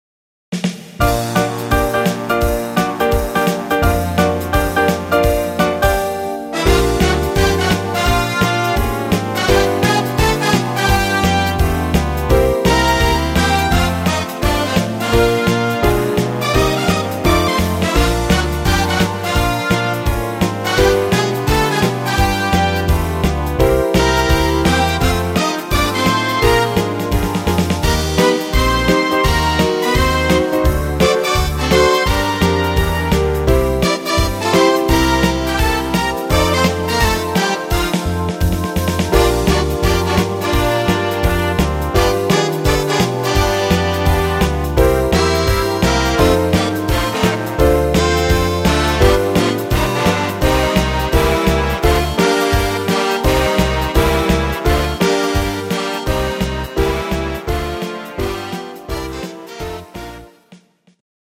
instr. Combo